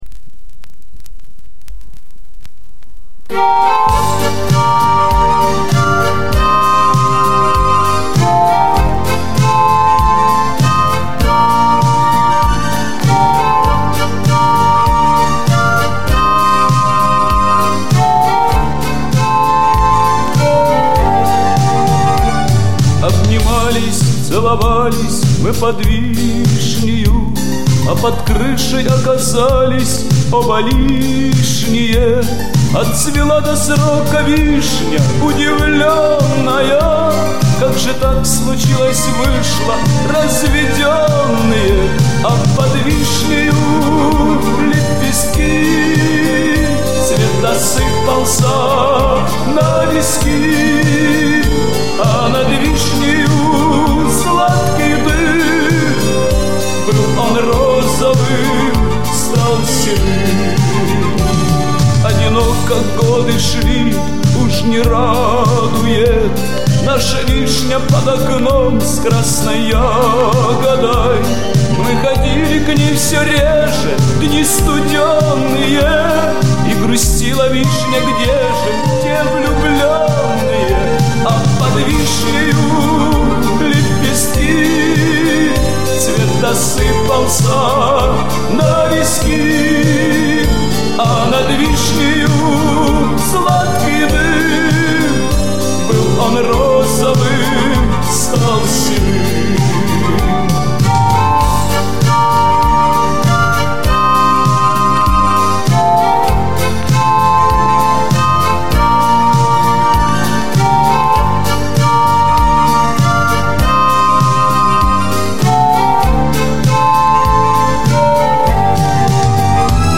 ОЦИФРОВКА С ПЛАСТИНКИ